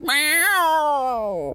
cat_scream_03.wav